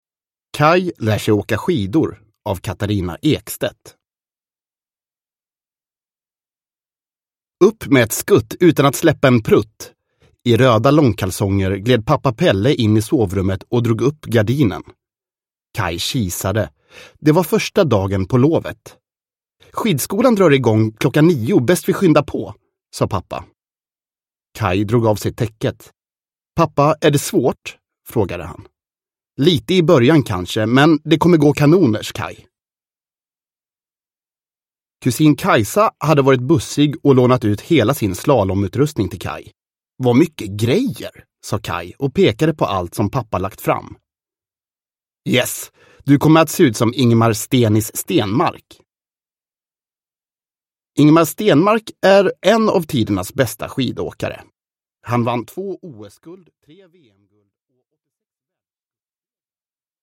Kaj lär sig åka skidor – Ljudbok